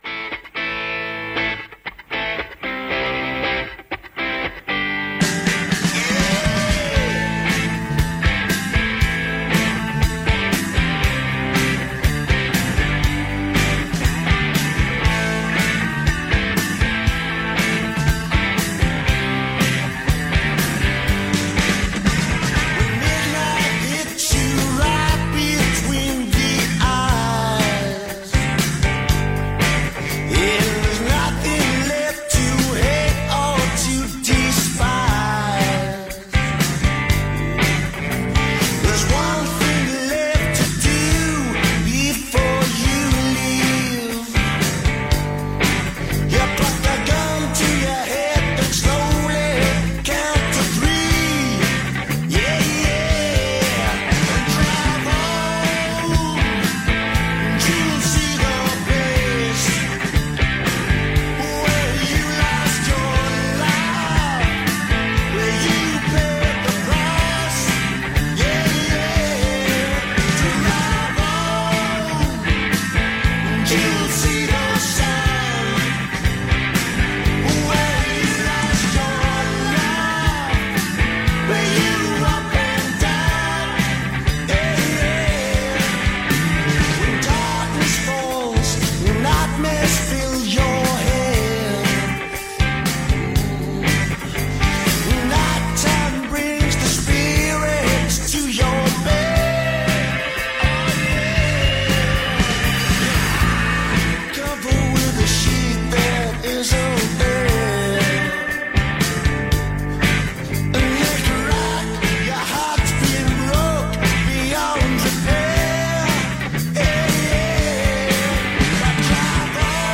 Bands of the 90s in session at the BBC.
Liverpool Indie band
vocals, guitar
bass guitar, vocals
drums